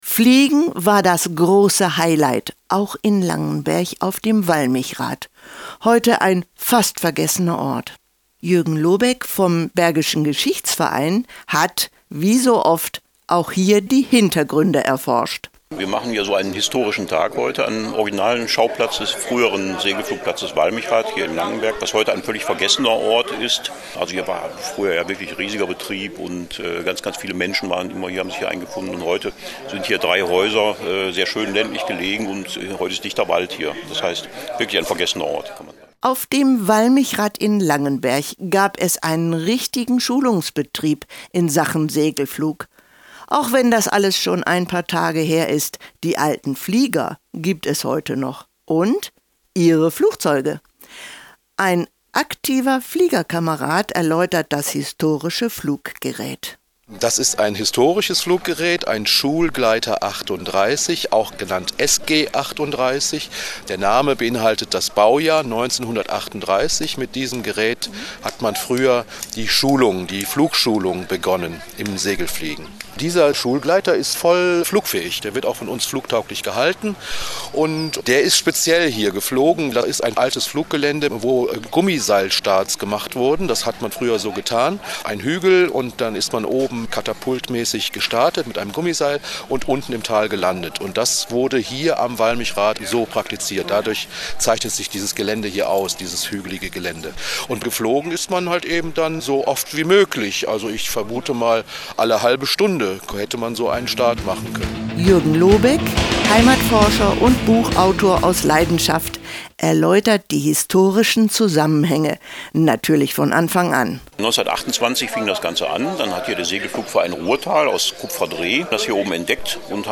BeitragSeideSenderSegelflug.mp3